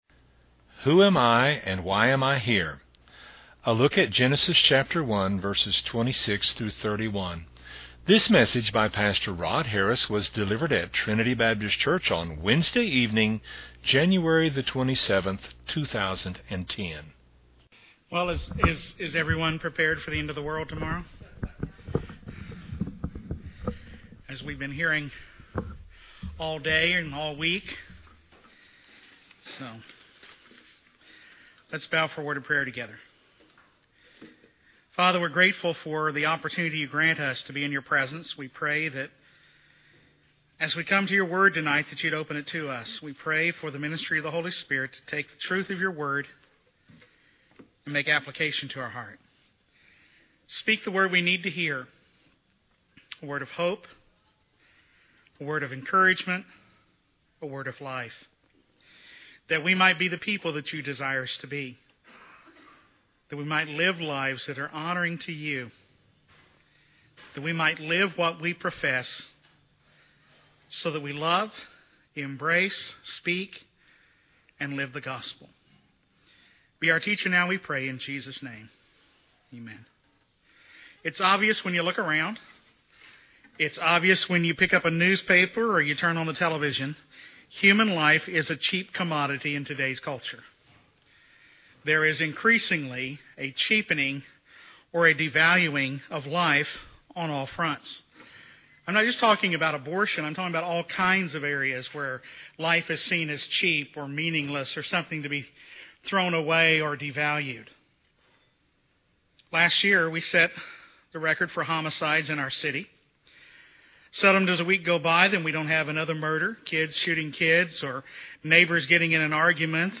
delivered at Trinity Baptist Church on Wednesday evening